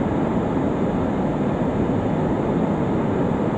autopilotloop.ogg